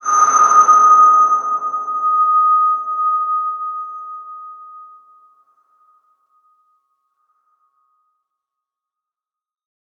X_BasicBells-D#4-pp.wav